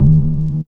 12SYN.BASS.wav